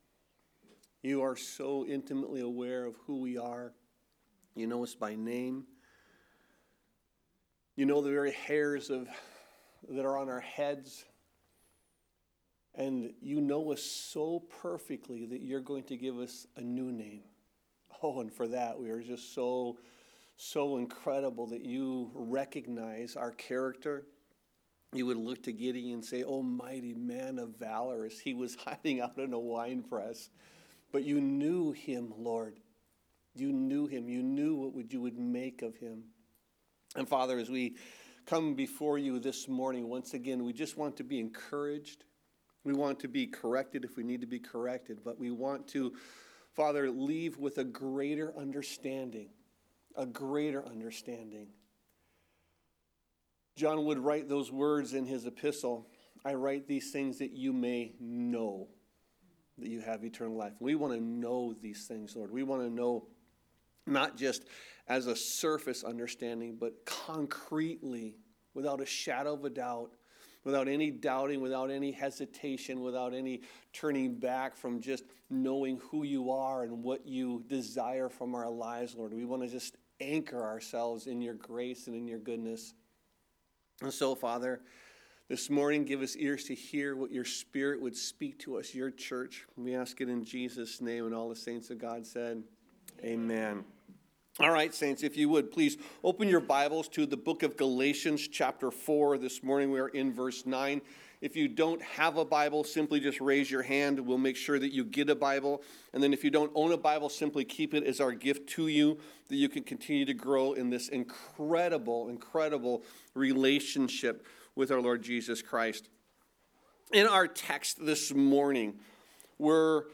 Sermons | Calvary Chapel Milwaukee
9 "After you have known God" on 1/18/2025 for our Sunday Service.